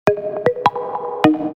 • Качество: 320, Stereo
без слов
короткие
электронные